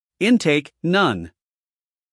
英音/ ˈɪnteɪk / 美音/ ˈɪnteɪk /